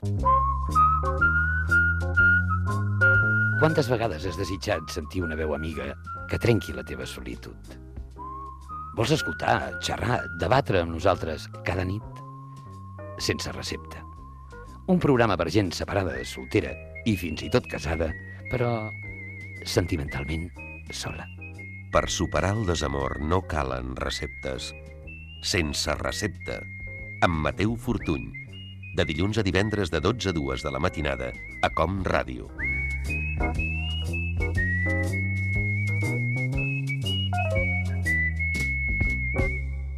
Promoció del programa
Entreteniment
FM